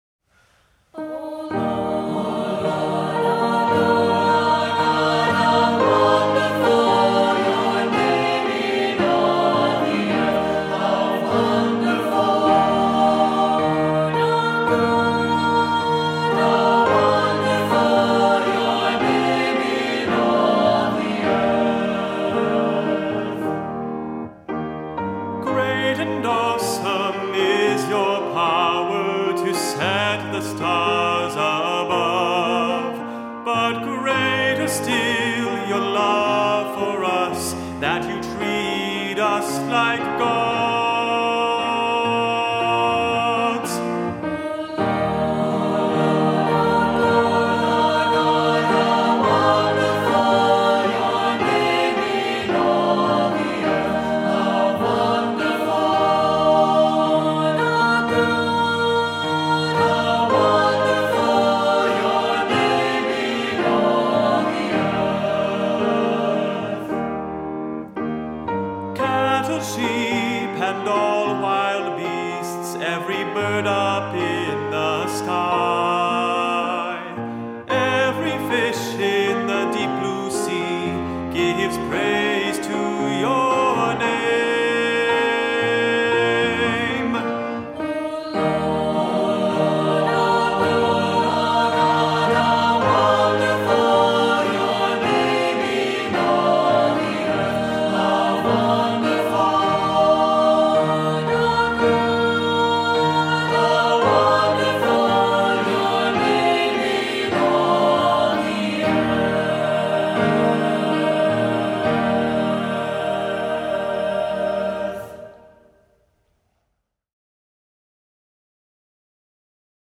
Voicing: SAT, assembly